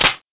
assets/psp/nzportable/nzp/sounds/weapons/tesla/clipin.wav at a21c260aab705f53aee9e935cc0f51c8cc086ef7